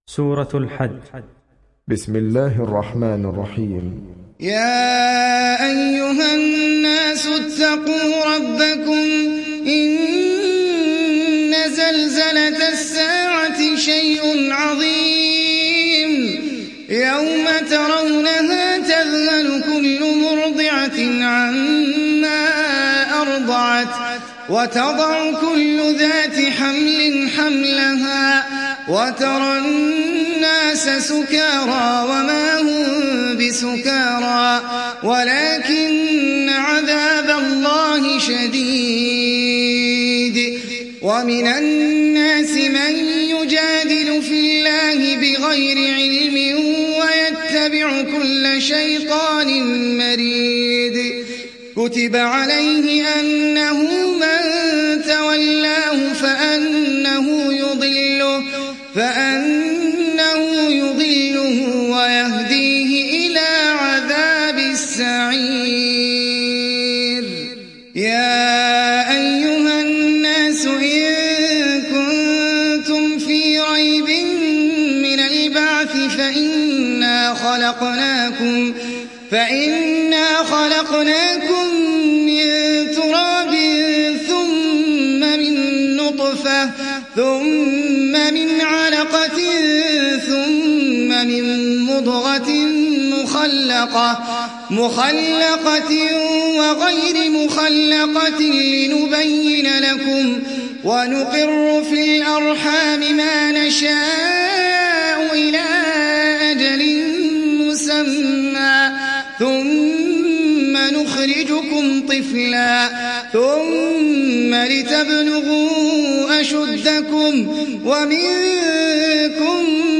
تحميل سورة الحج mp3 بصوت أحمد العجمي برواية حفص عن عاصم, تحميل استماع القرآن الكريم على الجوال mp3 كاملا بروابط مباشرة وسريعة